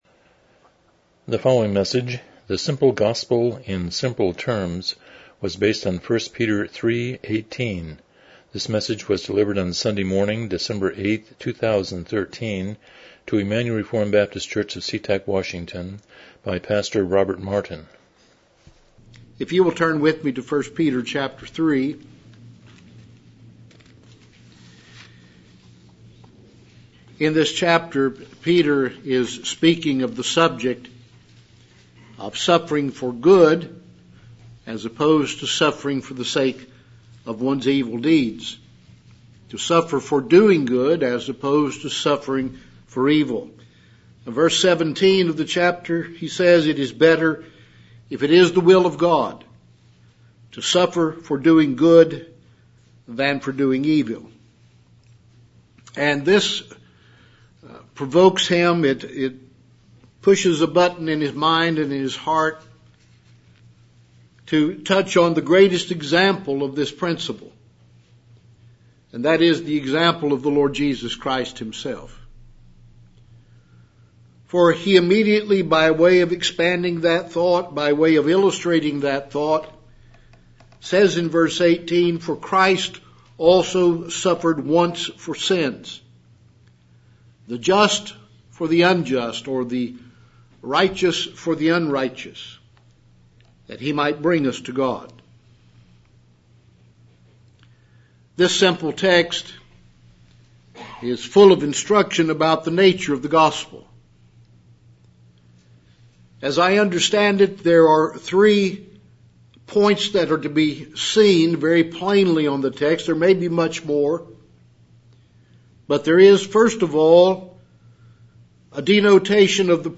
Miscellaneous Passage: 1 Peter 3:18 Service Type: Morning Worship « 22 The Sovereignty of God